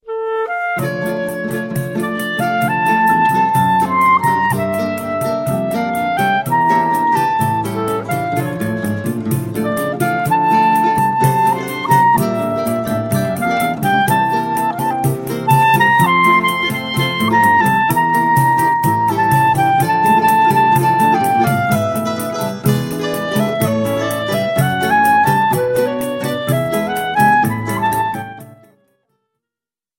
clarinet
Choro ensemble